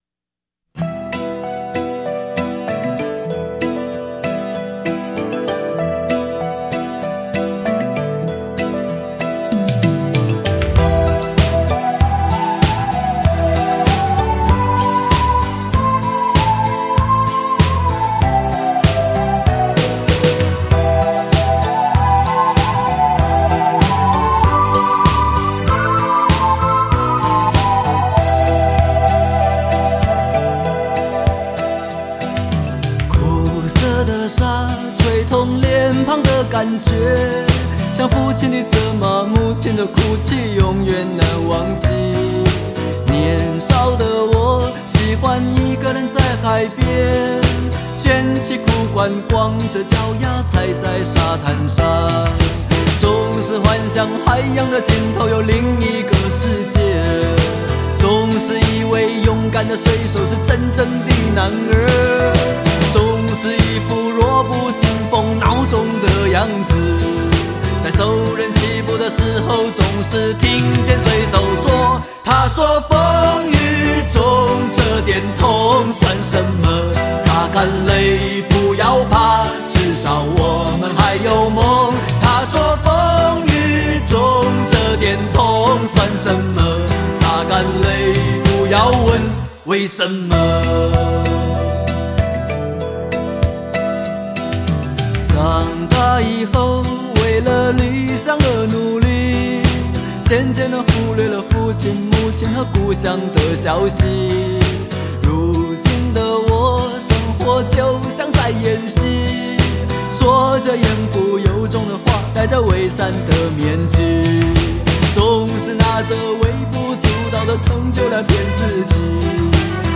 LP黑胶 精装10碟 HI-FI音质